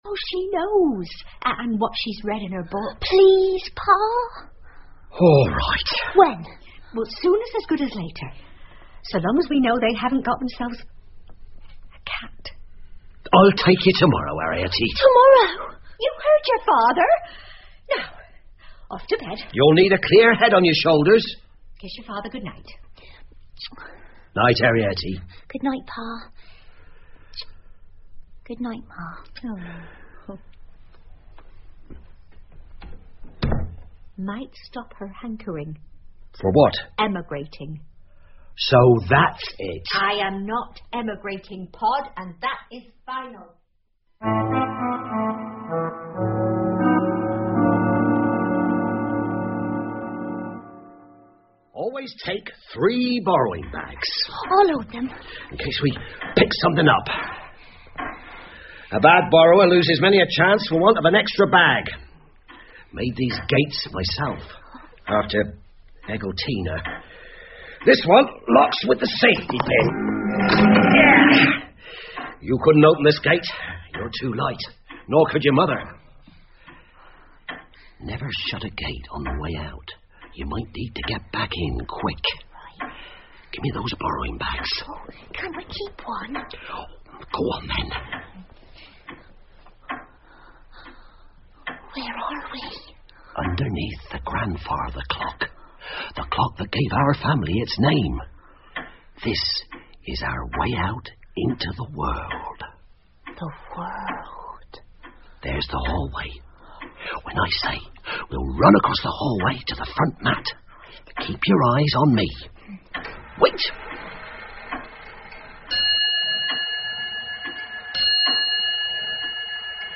借东西的小人 The Borrowers 儿童广播剧 4 听力文件下载—在线英语听力室